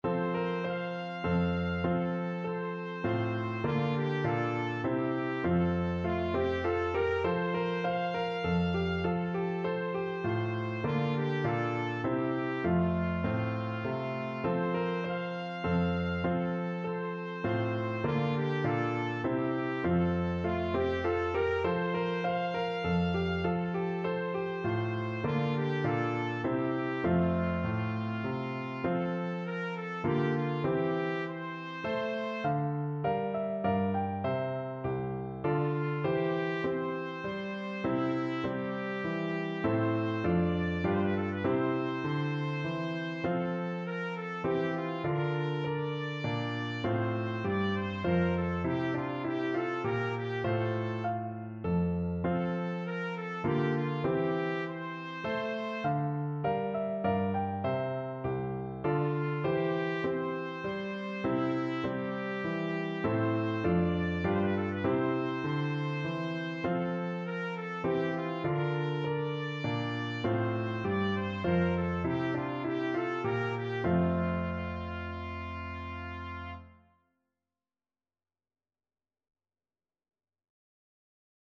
Trumpet
F major (Sounding Pitch) G major (Trumpet in Bb) (View more F major Music for Trumpet )
Moderato = c.100
3/4 (View more 3/4 Music)
Classical (View more Classical Trumpet Music)
Telemann_minuet_g_TPT.mp3